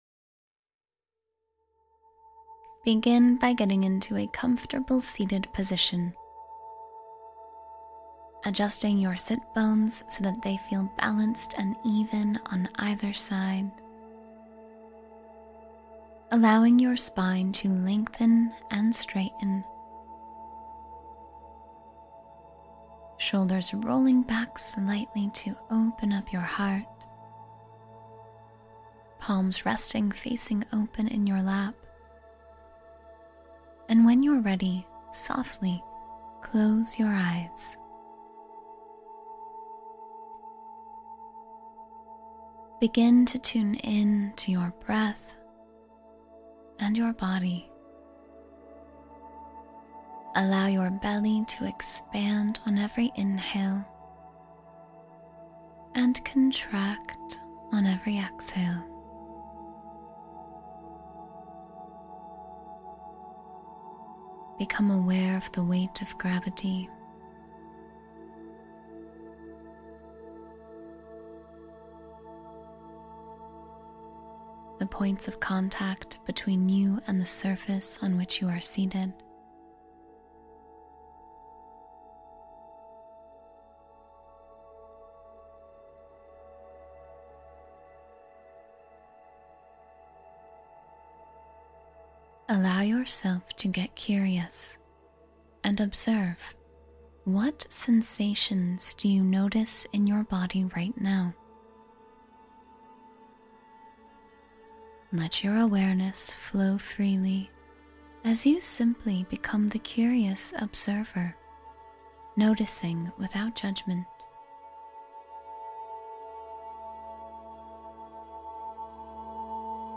Guided Happiness Activation Meditation — Awaken Joy and Positivity